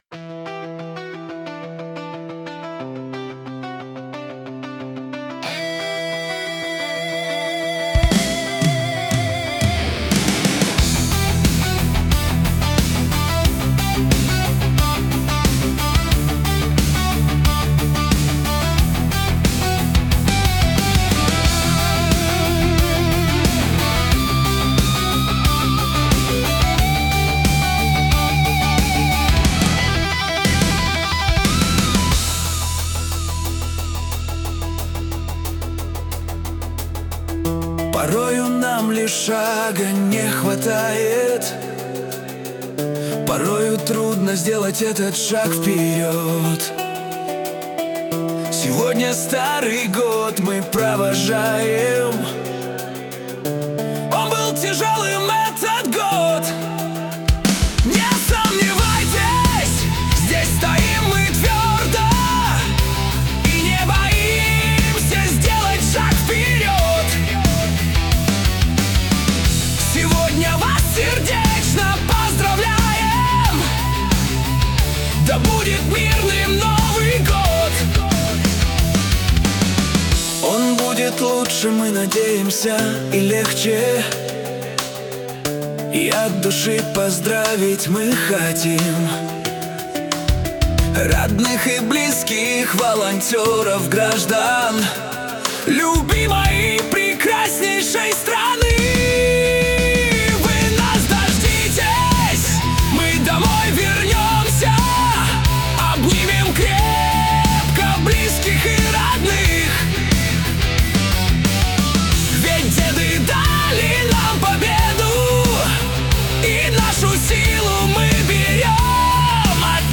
Песня «Не сомневайтесь»